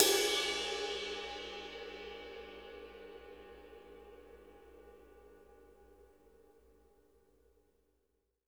MPBELL    -R.wav